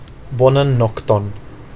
Bonan nokton [ˈbo.nan ˈnok.ton]